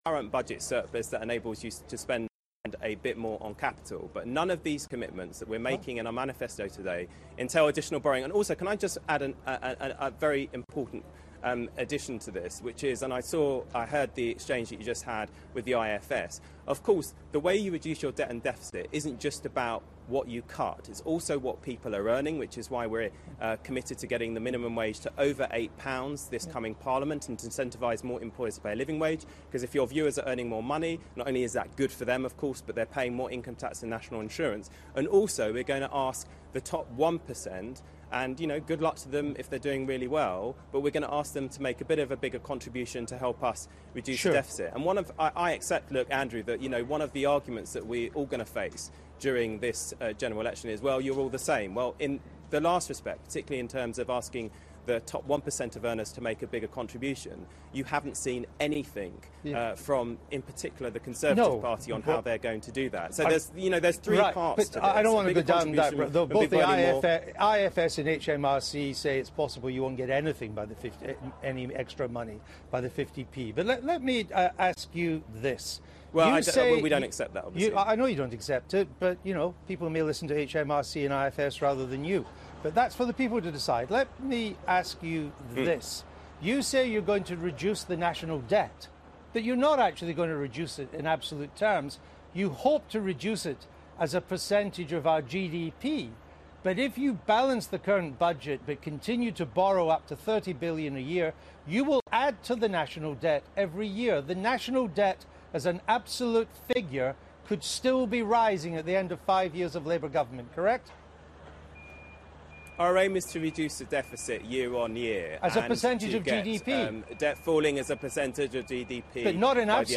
Chuka Umunna was challenged by Andrew Neil over Labour's commitment to balancing the books. BBC Daily Politics, 13 April 2015